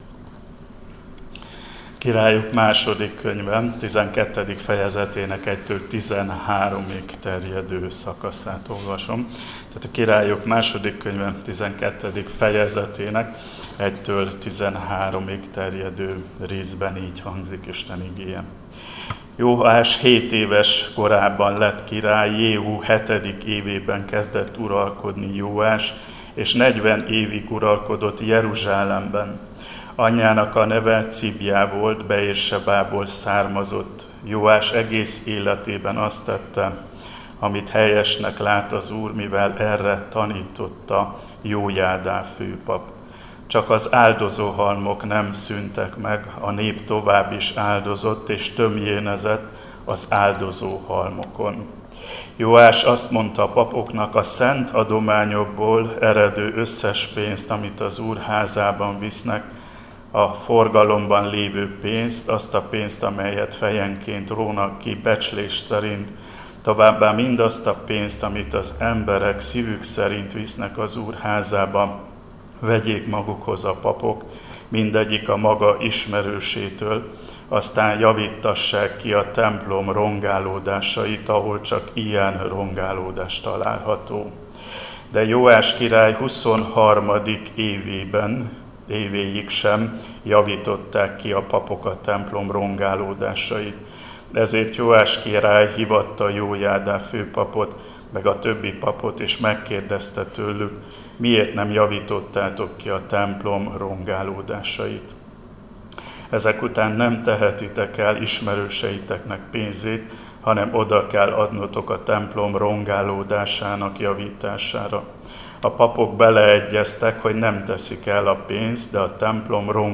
Áhítat, 2024. június 4.